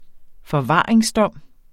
Udtale [ fʌˈvɑˀeŋs- ]